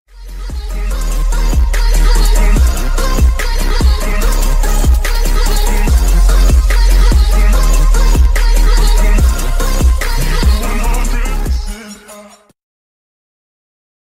Twitch and YouTube Follower & Donation Sound
twitch-follower-_-sub-_-donate-sound-effect-alert-_1-online-audio-converter.mp3